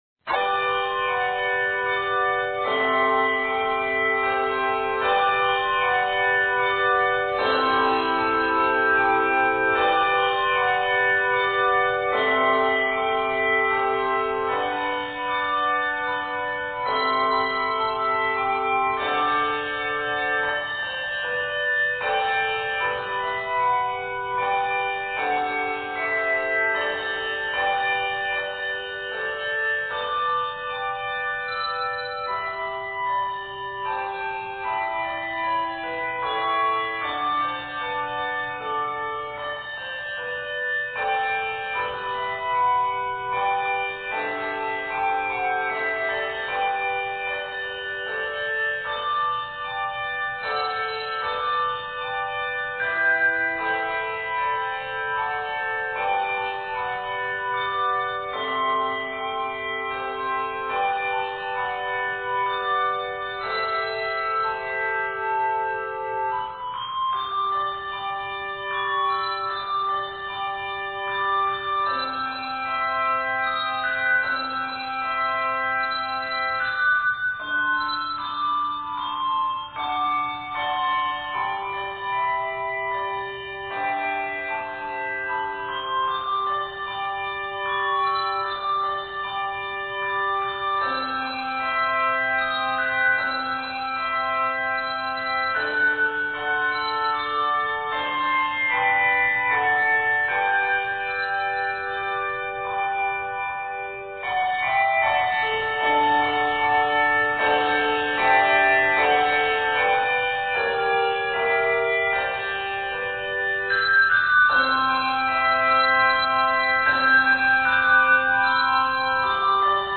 Set with prayerful care in a simple, direct style